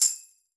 Dilla Tambo 02.wav